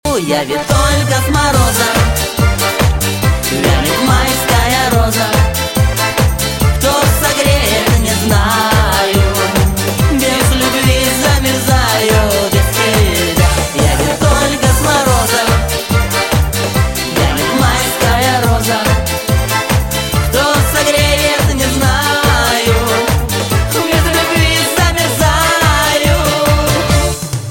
Жанр: Эстрада